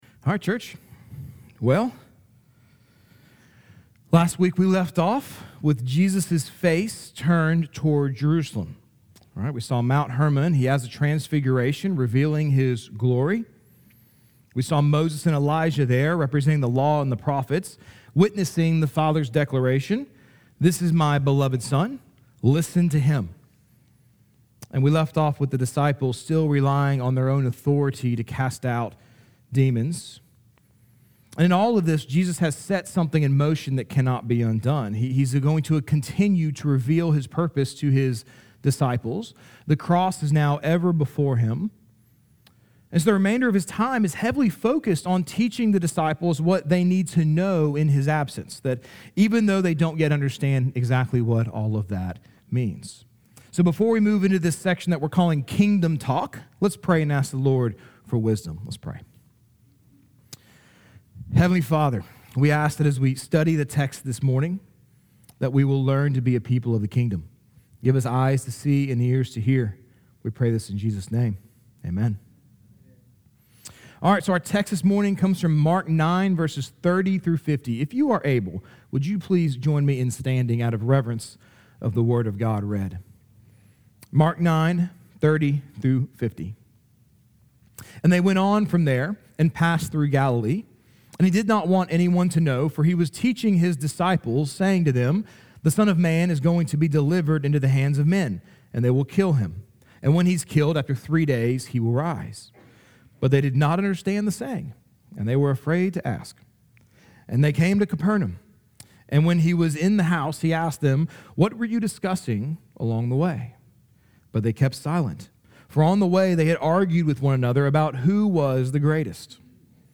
Sermons | Grace Fellowship (EPC)